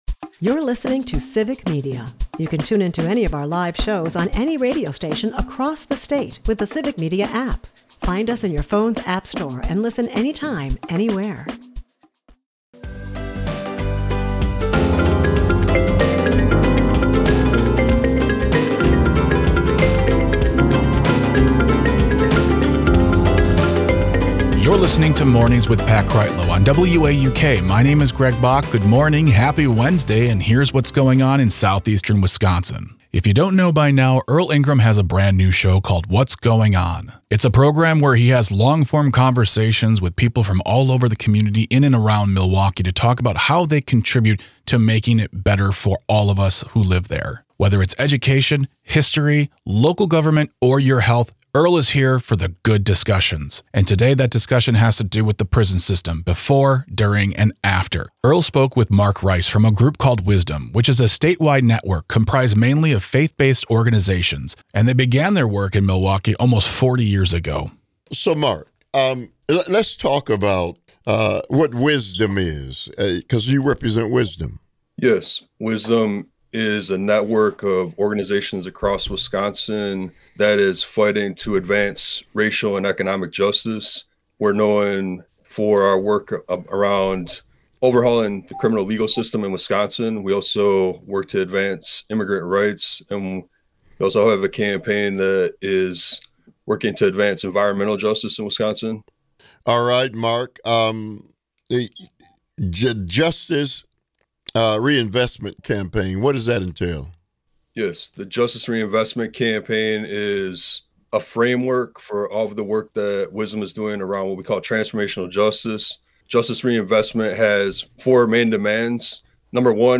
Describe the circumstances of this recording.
WAUK Morning Report is a part of the Civic Media radio network and air four times a morning.